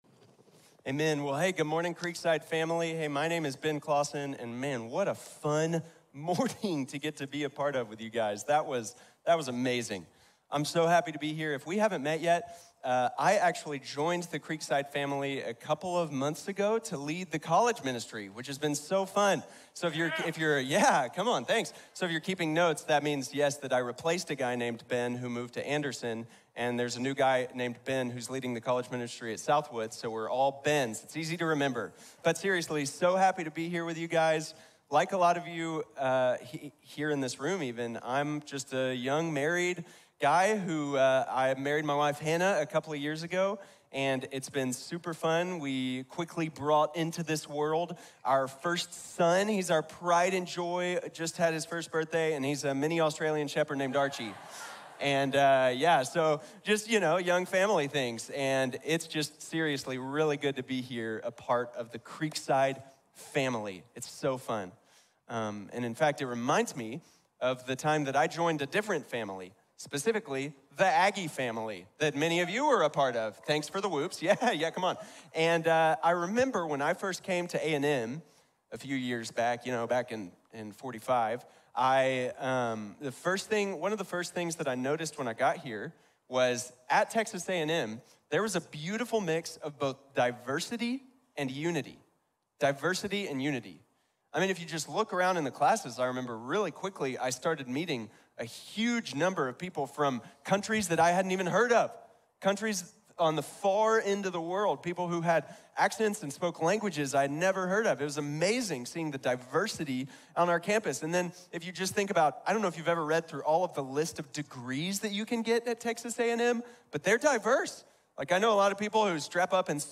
The Body of Christ | Sermon | Grace Bible Church